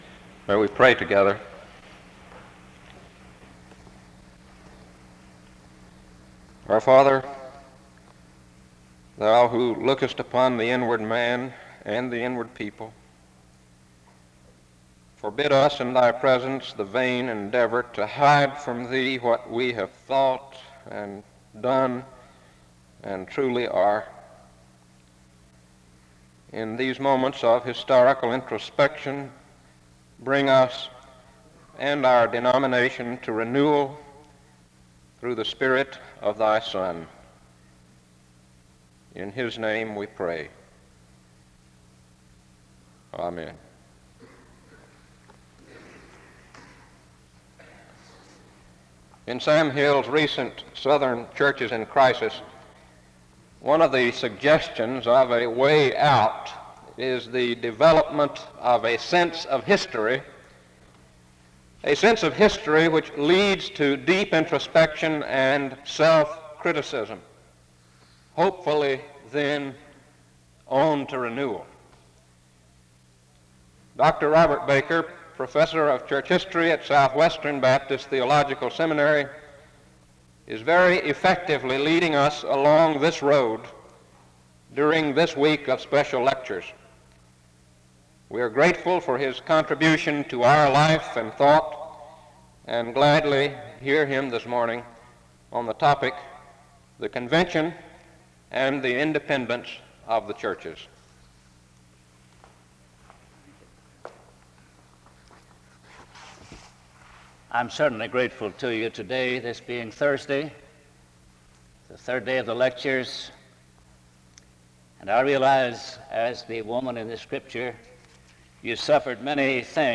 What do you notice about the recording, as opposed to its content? The service starts in prayer from 0:00-0:43. A closing prayer is given from 50:02-50:16. This is part 3 of a 4 part lecture series. SEBTS Chapel and Special Event Recordings